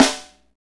Index of /90_sSampleCDs/Northstar - Drumscapes Roland/SNR_Snares 1/SNR_Funk Snaresx